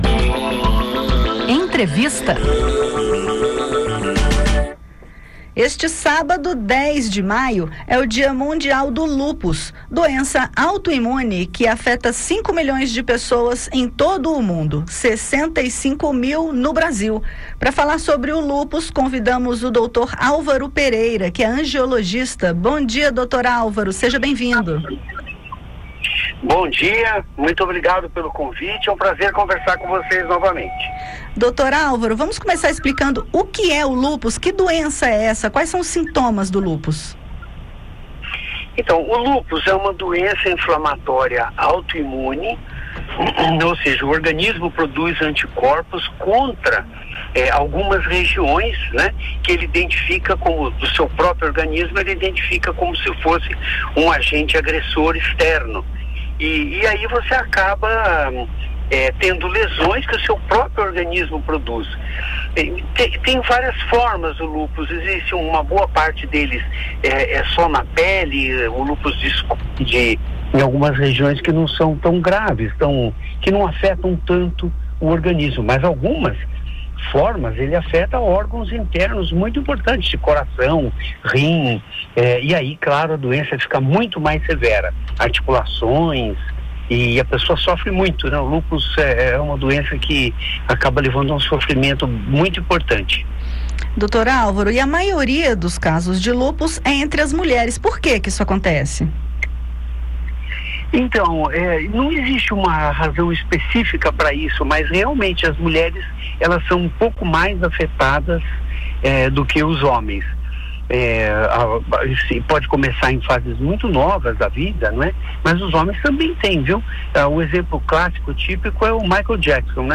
Lúpus vai além da pele: angiologista detalha sintomas e tratamentos possíveis